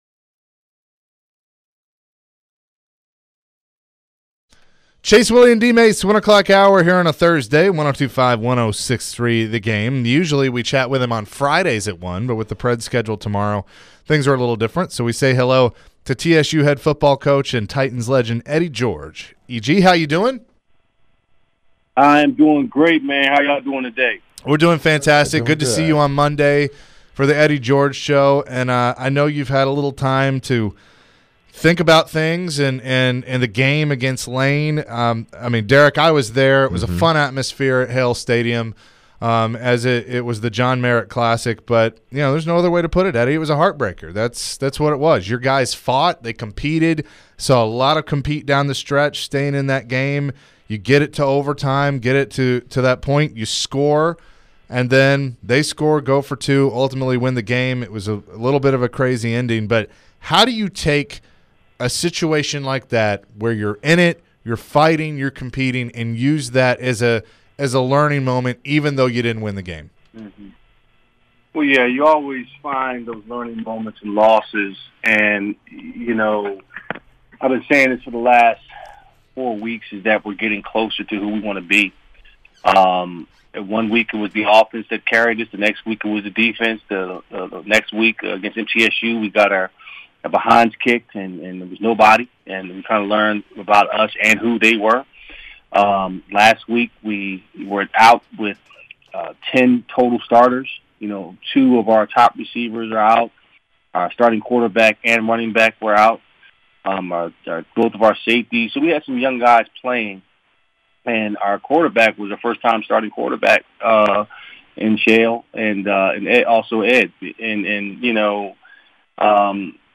Eddie George Full Interview (10-06-22)